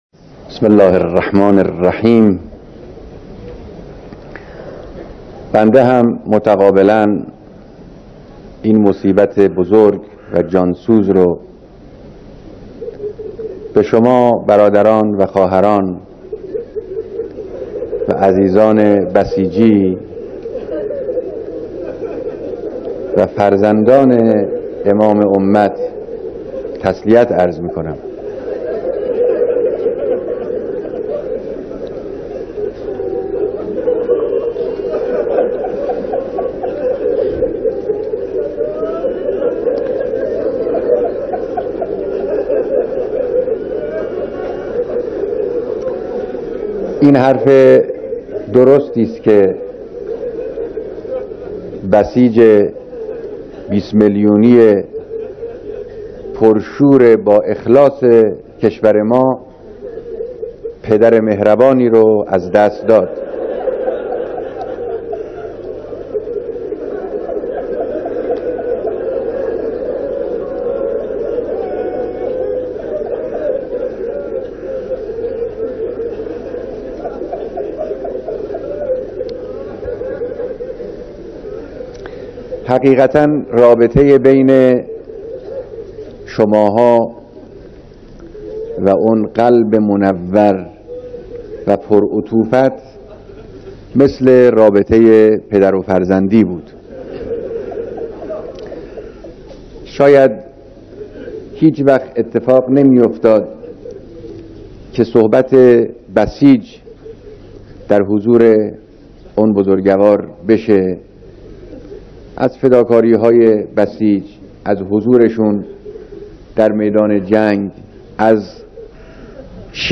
بيانات در جمع كثيري از بسيجيان نمونۀ از اقشار مختلف سرتاسر كشور
بیانات در مراسم بیعت بسیجیان نمونه کشور